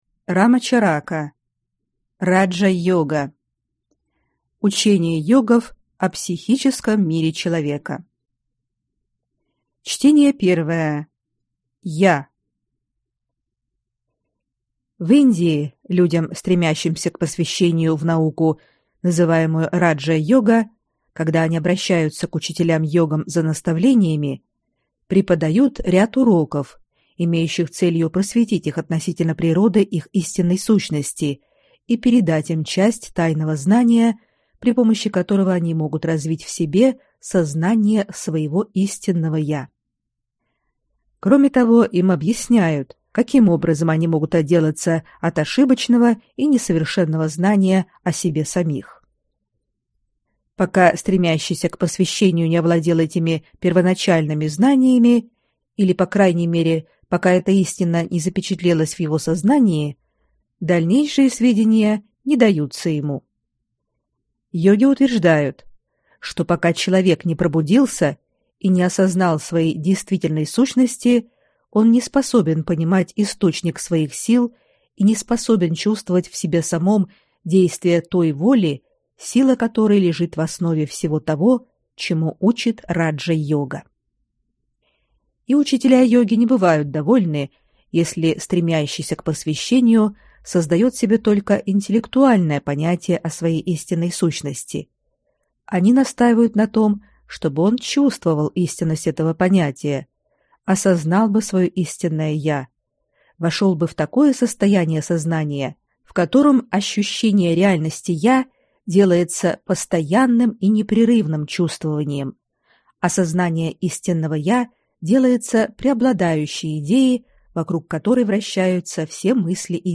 Аудиокнига Раджа-йога | Библиотека аудиокниг
Прослушать и бесплатно скачать фрагмент аудиокниги